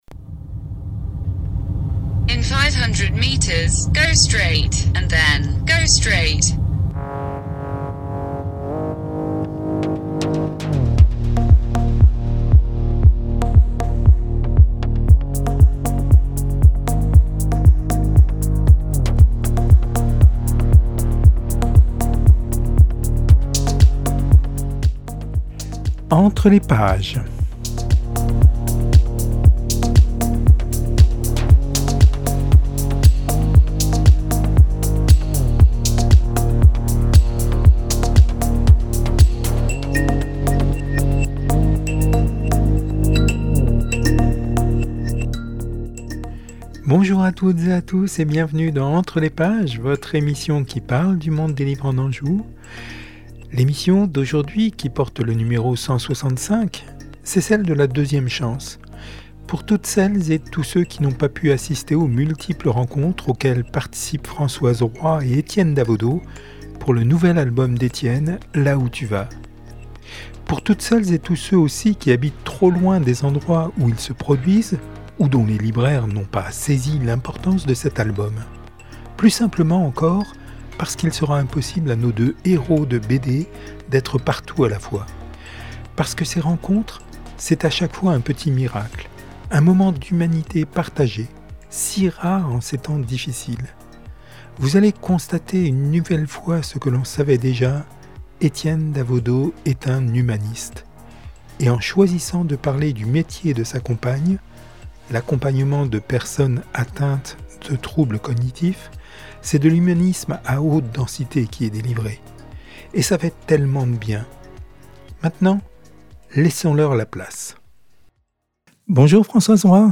ENTRE LES PAGES, c’est une heure consacrée à l’univers des livres en Anjou. Interviews, reportages, enquêtes, sont au menu.